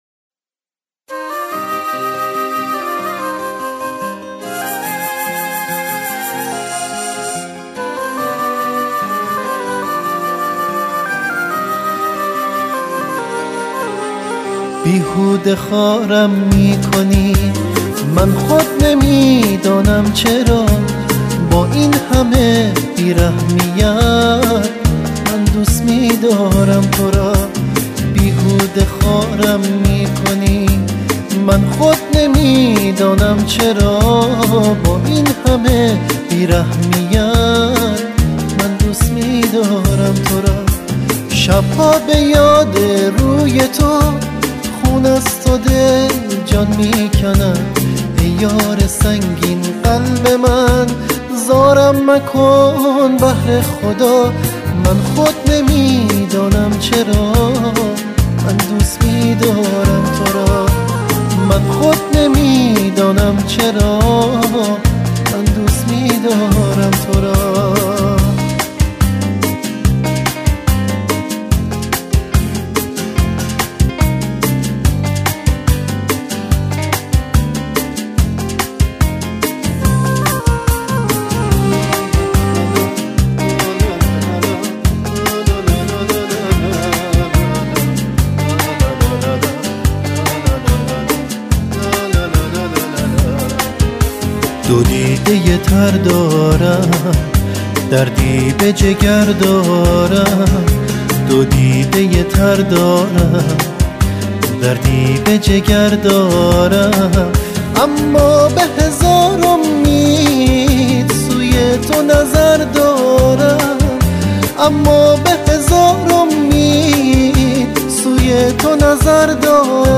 دانلود آهنگ غمگین